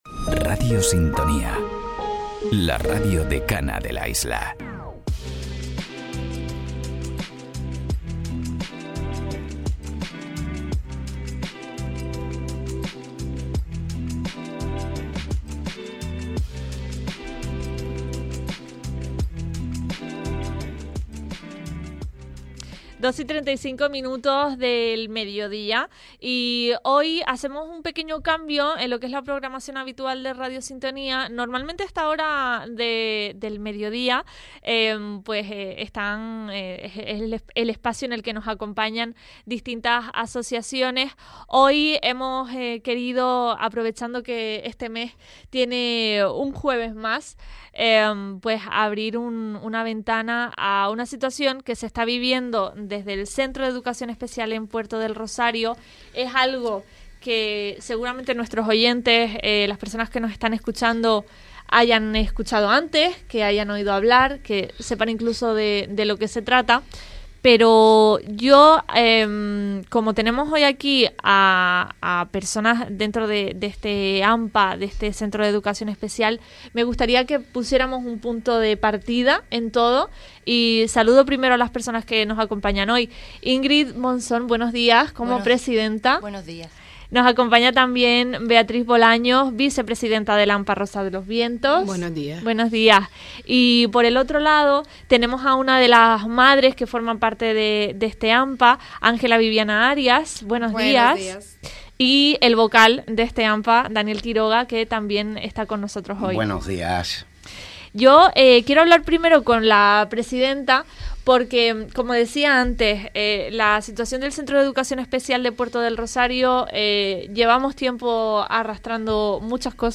Entrevista a miembros del AMPA Rosa de los Vientos del CEE Puerto del Rosario - Radio Sintonía